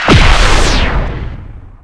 fire_plasma3.wav